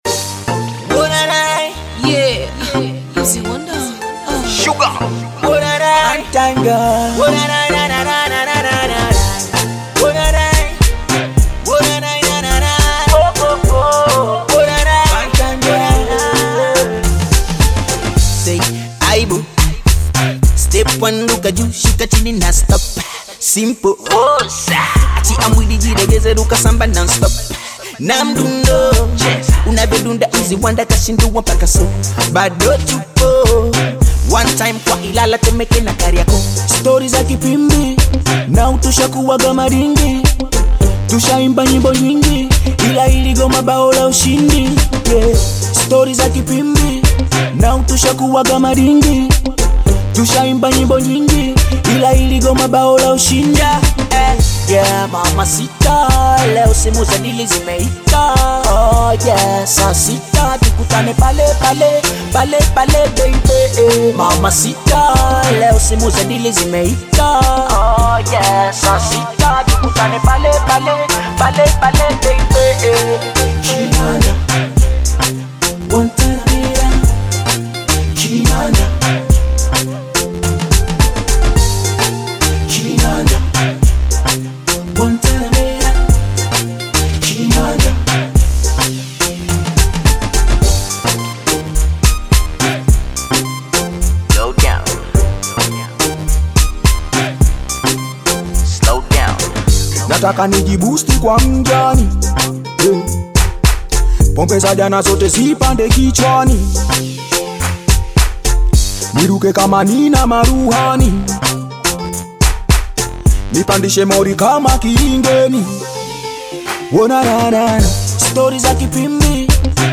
vibrant Bongo Flava single